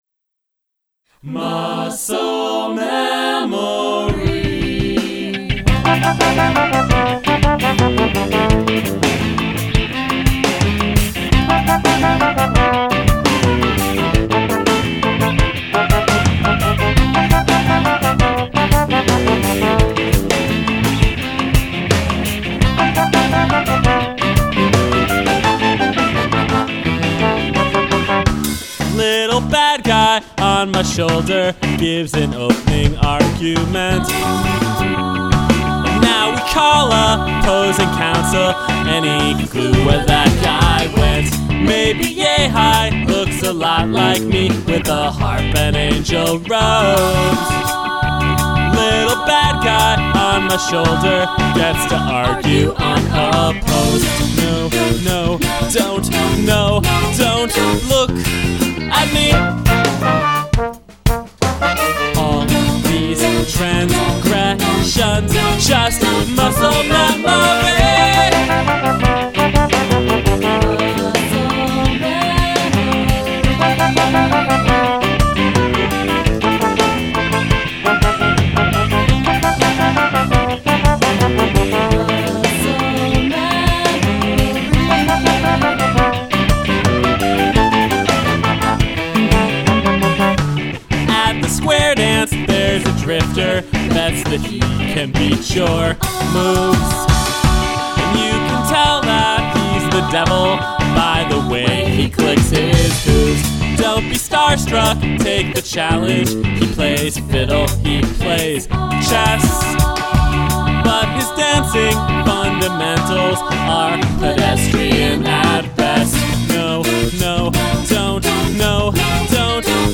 additional guitar
additional vocals
trombone
trumpet, additional trombone
tenor saxophone